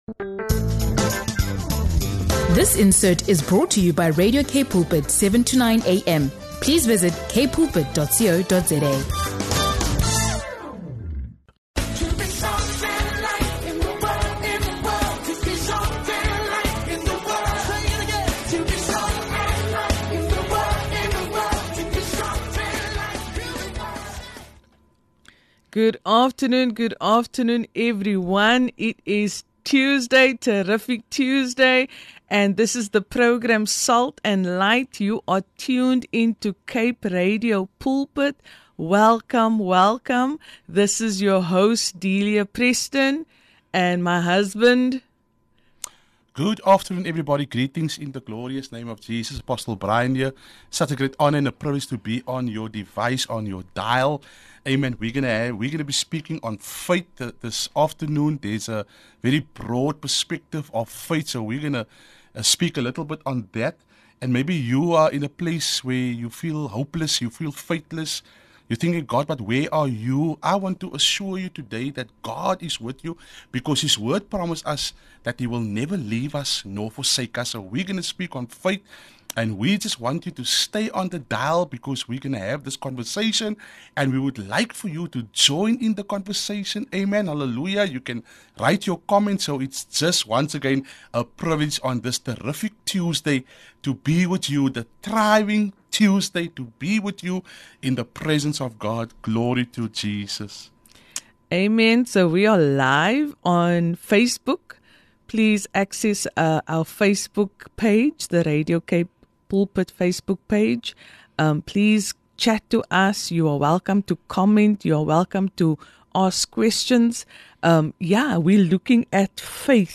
Tune in for a powerful conversation on faith, obedience, and the transformative power of belief in God.